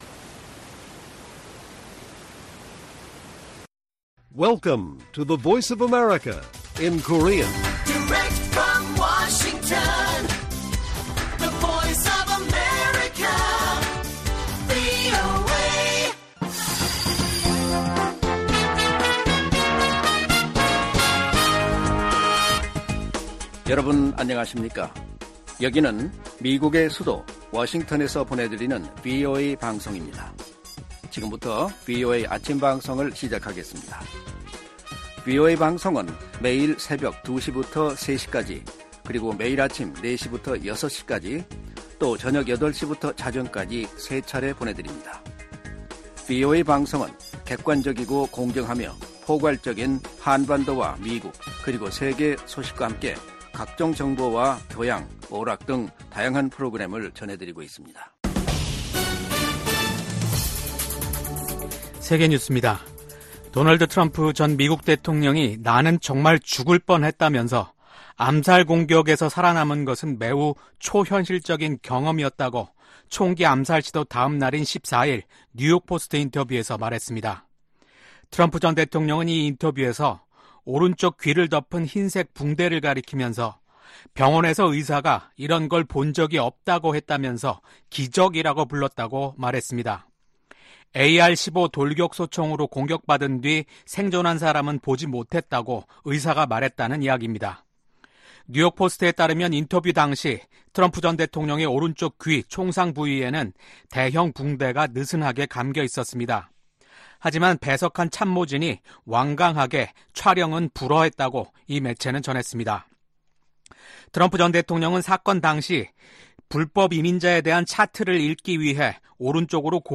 세계 뉴스와 함께 미국의 모든 것을 소개하는 '생방송 여기는 워싱턴입니다', 2024년 7월 16일 아침 방송입니다. '지구촌 오늘'에서는 2분기 중국 경제성장률이 예상에 못 미치는 수치가 나온 소식 전해드리고 '아메리카 나우'에서는 지난 주말 도널드 트럼프 전 대통령의 선거 유세 현장에서 총격 사건이 발생해 전 세계가 큰 충격에 빠진 소식 전해드립니다.